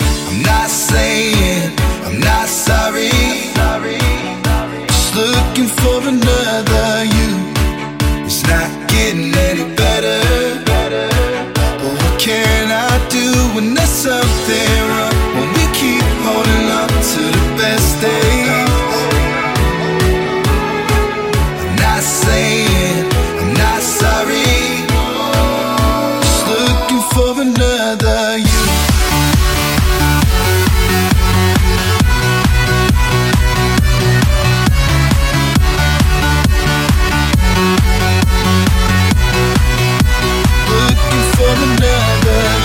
club